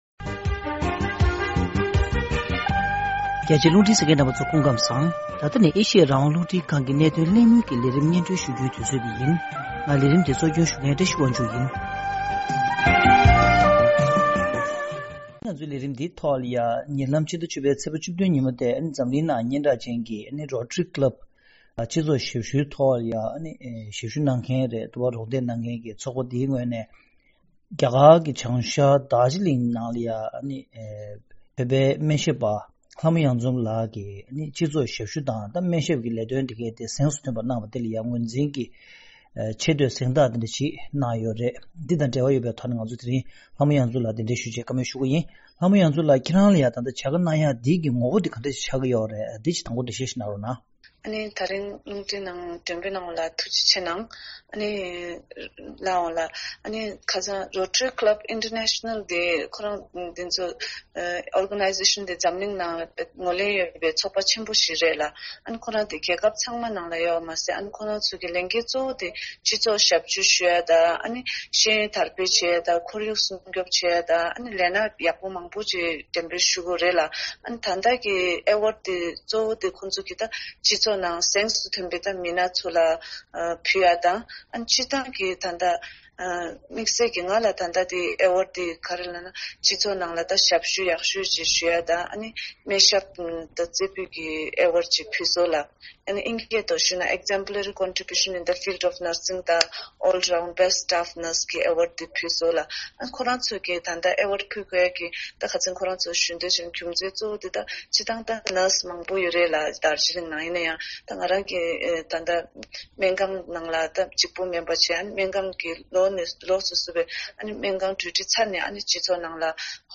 གནད་དོན་གླེང་མོལ་གྱི་ལས་རིམ་ནང་།